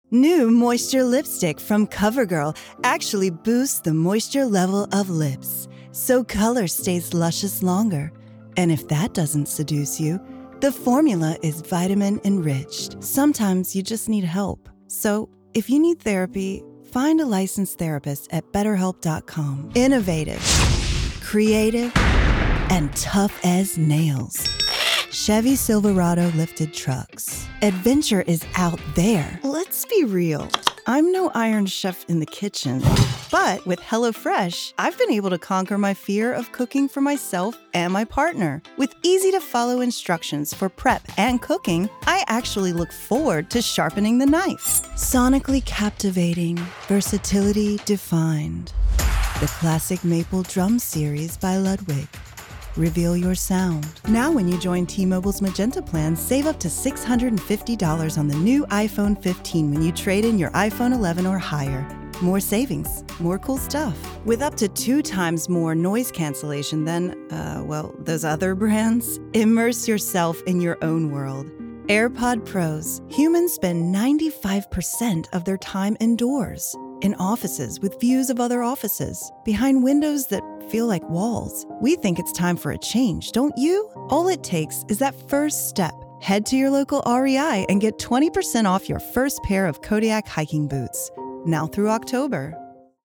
Voiceover Demo